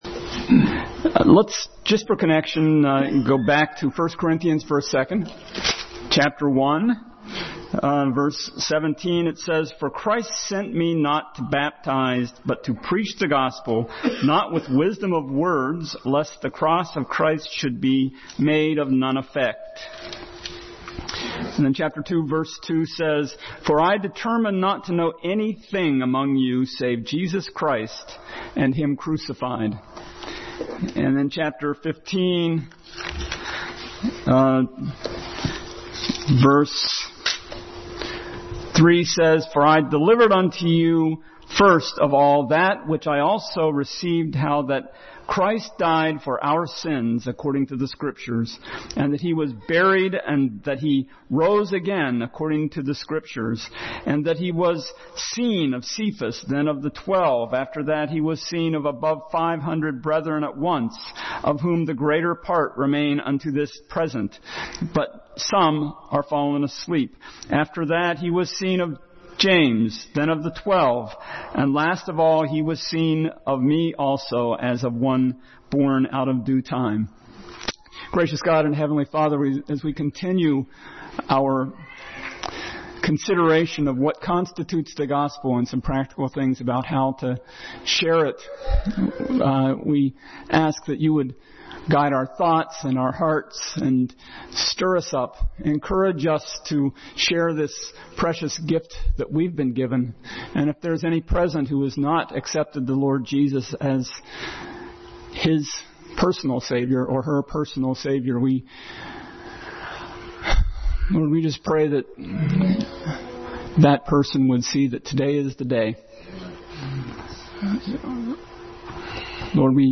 Paul and the Corinthians – Continued Passage: 1 Corinthians 1:17, Acts 17:22-34, 1 Corinthians 15:20-28 Service Type: Family Bible Hour